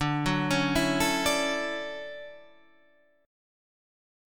D Major 9th